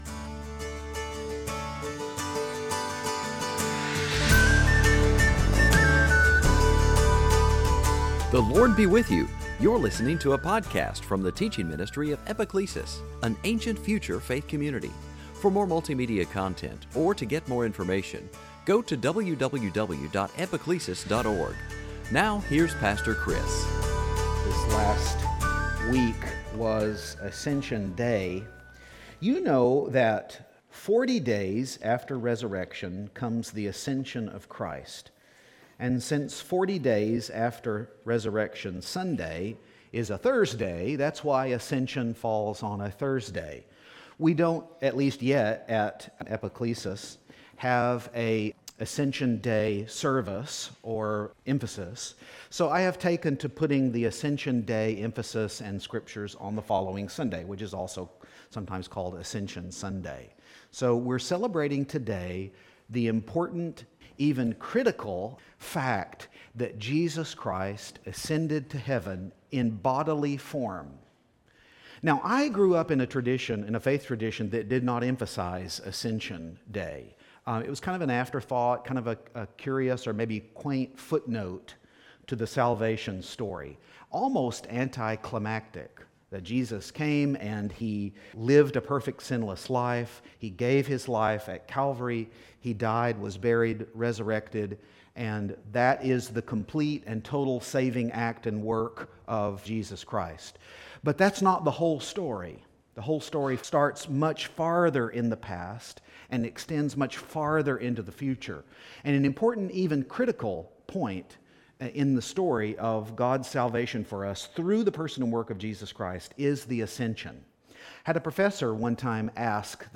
Series: Sunday Teaching Is the ascension of Christ a curious or quaint footnote to His ministry